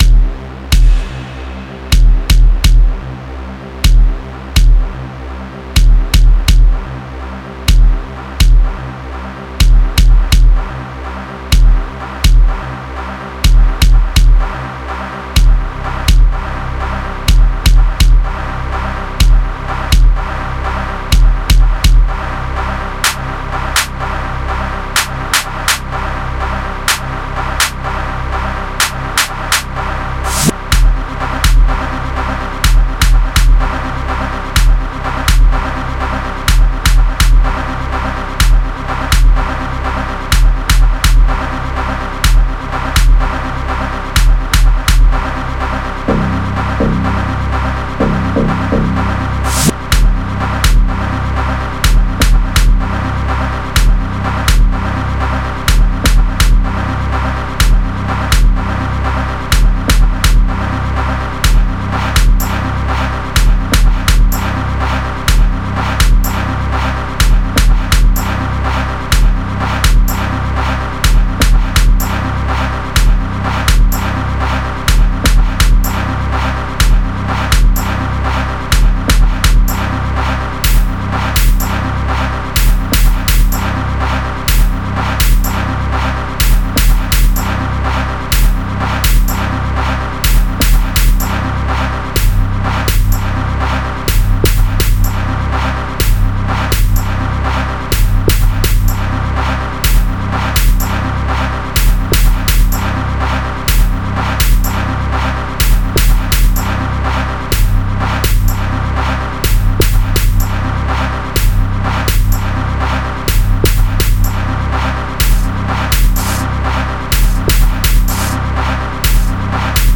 05:09 Genre : Gqom Size